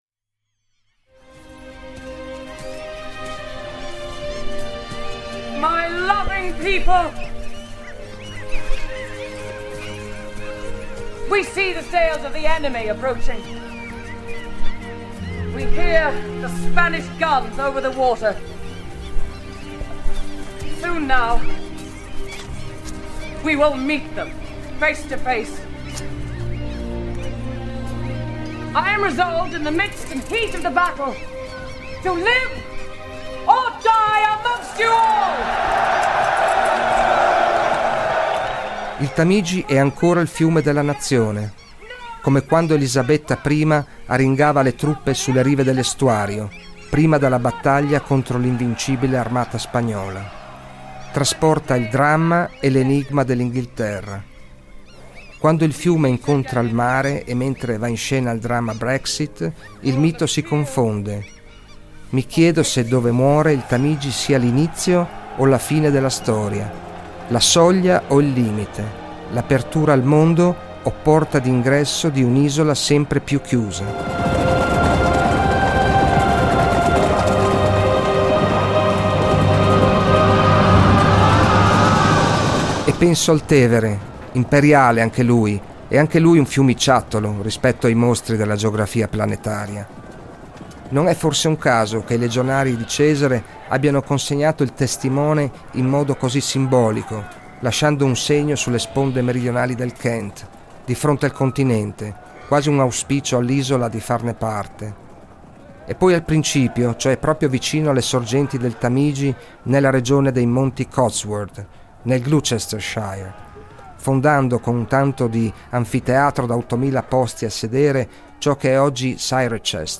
Lungo il percorso si ascoltano le voci di agricoltori, artigiani, imprenditori e si percepisce il divario rispetto alla megalopoli che detta le regole alla modernità globale.